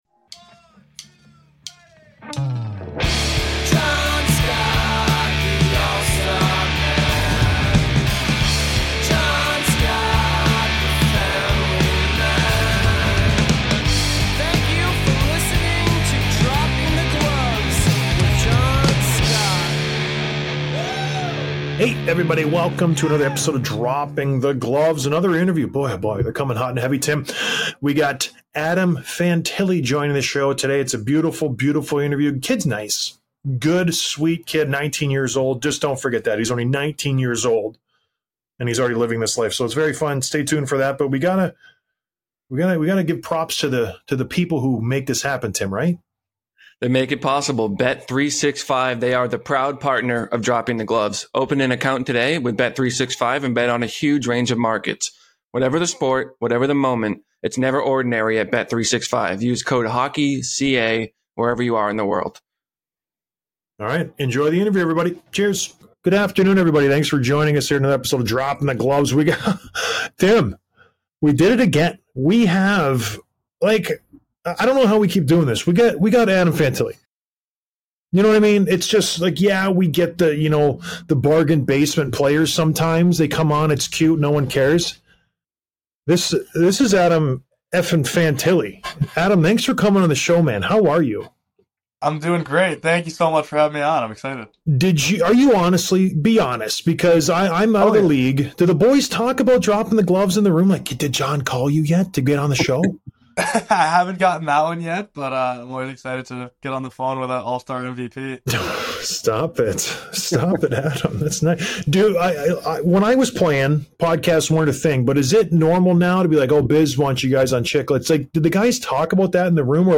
Interview with Adam Fantilli, Columbus Blue Jackets